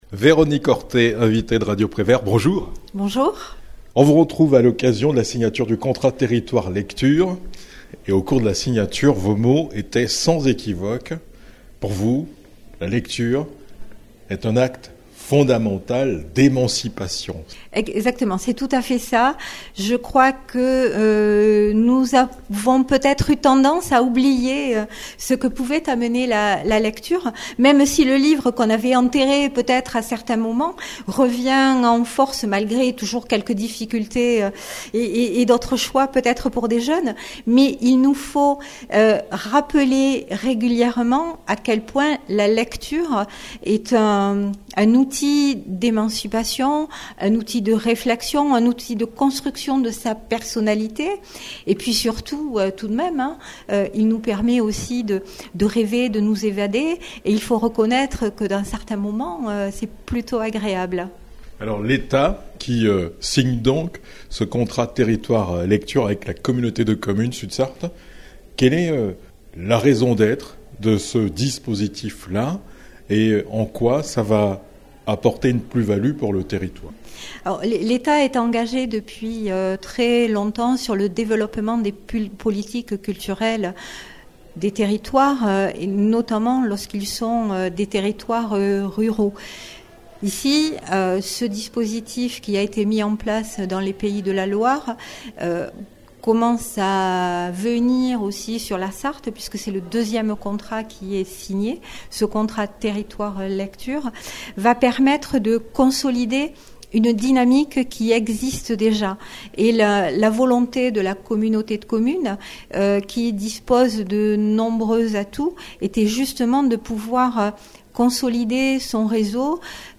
A l'issue de la cérémonie, François Boussard, Président de l'intercommunalité, Véronique Ortet, sous-préfète de l'arrondissement de La Flèche, Véronique Rivron, 1ère vice-présidente du Conseil départemental de la Sarthe, présidente de la Commission vie associative, culture, sport, tourisme et patrimoine, et Eric Martineau, député de la 3e circonscription de la Sarthe ont mis en exergue le rôle fondamental de la lecture dans l'émancipation du citoyen.